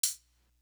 Latin Thug Hat.wav